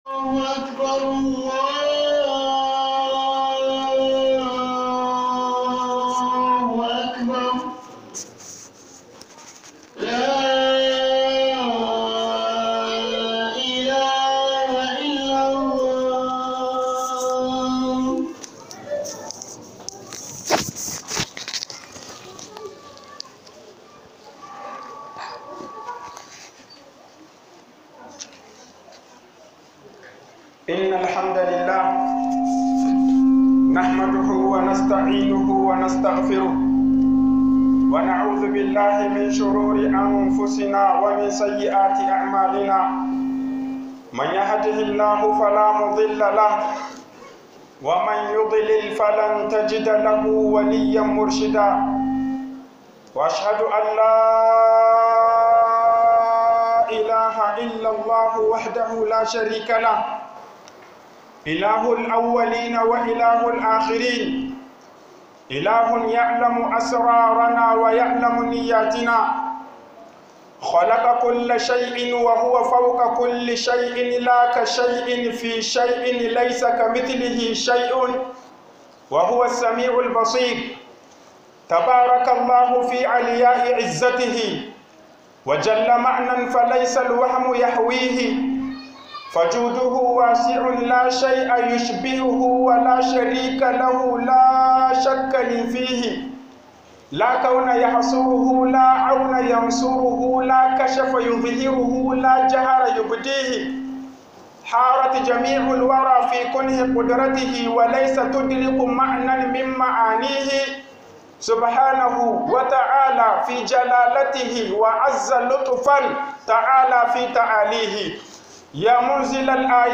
hudubar markaz (2) - HUƊUBOBIN JUMA'A